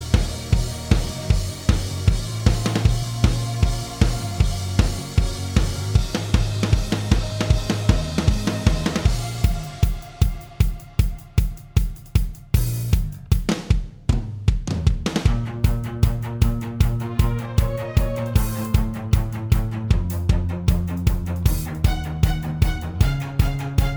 Minus Main Guitar Pop (2010s) 3:20 Buy £1.50